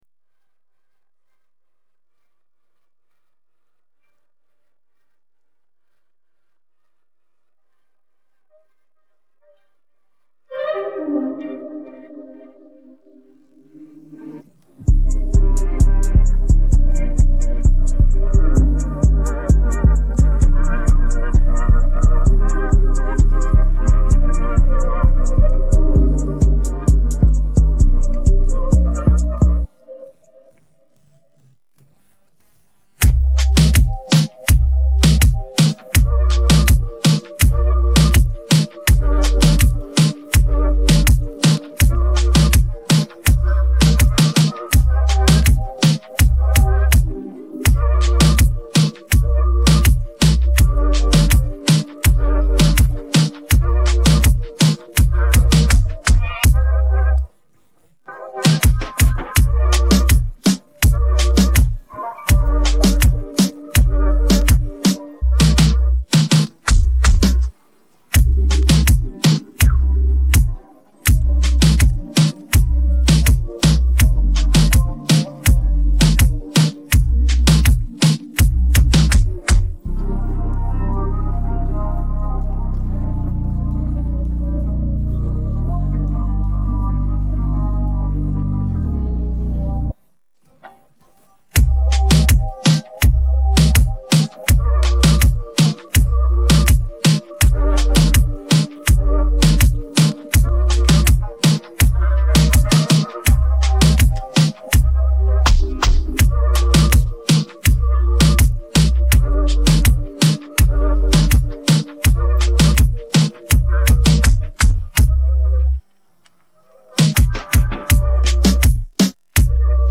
Musique de fond